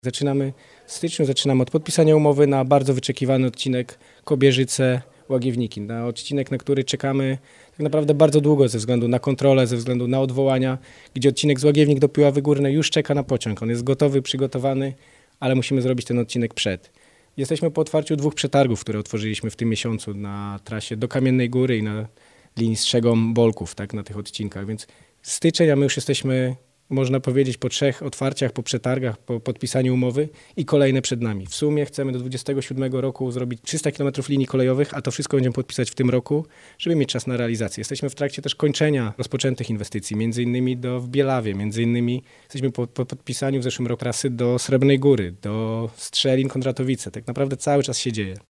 Jak zapowiada Michał Rado, wicemarszałek Województwa Dolnośląskiego, 2026 rok ma być rekordowy pod względem podpisywania umów inwestycyjnych, co pozwoli znacząco przyspieszyć realizację kolejnych projektów.